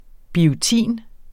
Udtale [ bioˈtiˀn ]